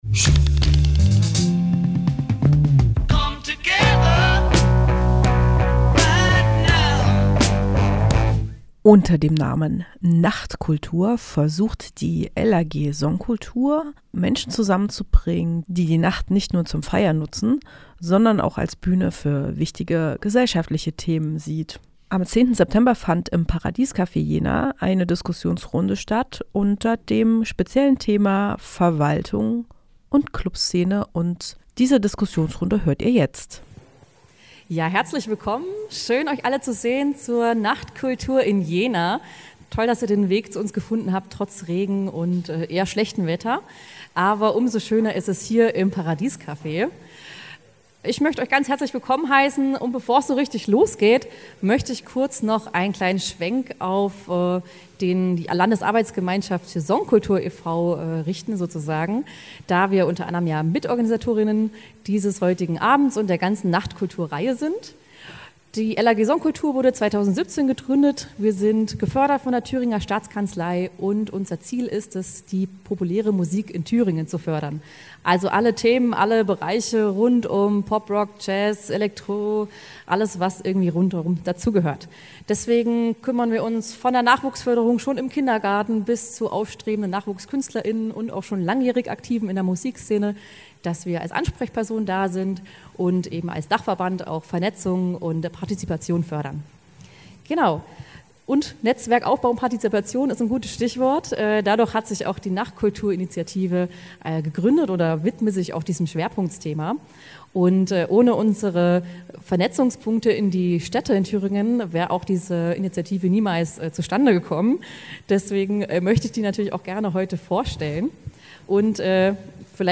Mitschnitt der Podiumsdiskussion im Paradies Café zum Thema Nachtkultur in Jena vom 10.9.2025. Initiatorin ist die LAG Songkultur Thüringen.
n8_kultur_podcast.mp3